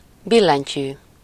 Ääntäminen
IPA : /kʰiː/